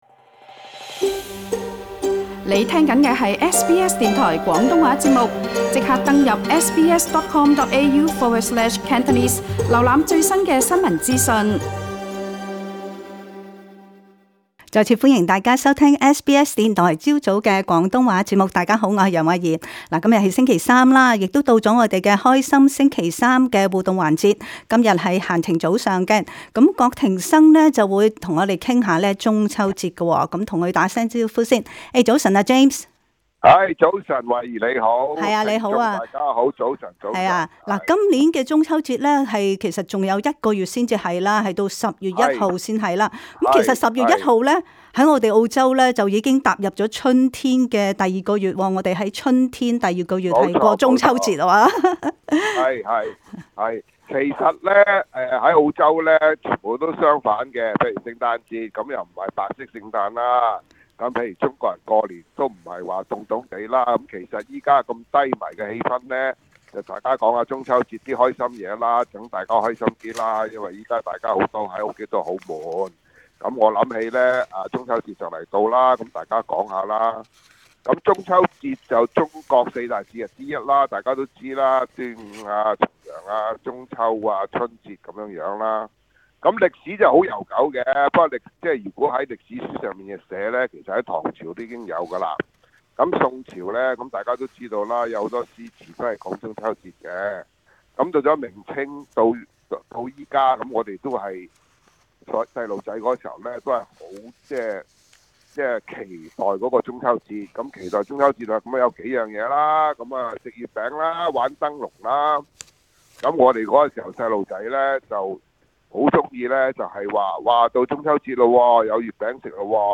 聽眾並打電話來分享過往中秋節開心的情景。